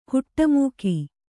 ♪ huṭṭa mūki